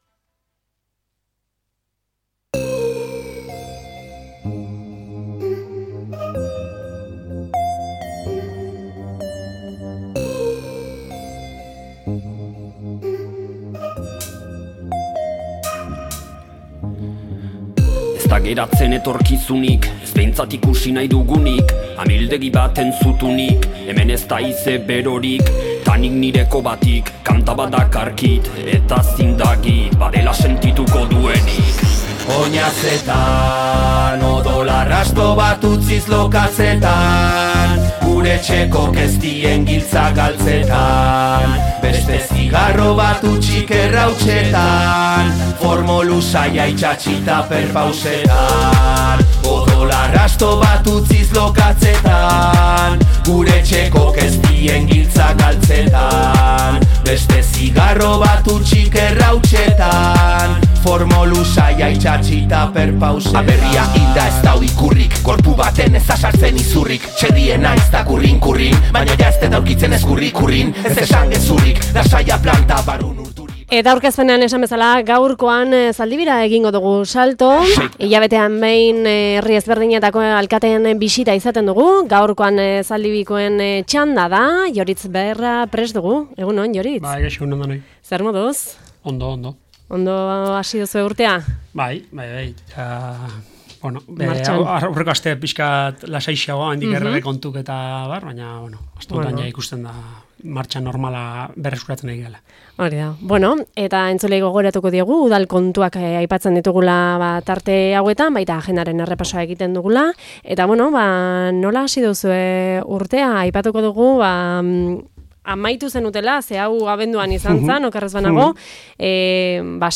ALKATEEKIN ELKARRIZKETAN – ZALDIBIA – Segura Irratia
Gaurkoan Zaldibiko alkatea den Ioritz Berra izan dugu. Urte hasierako kontuak edota urtarrileko agenda aipatu dizkigu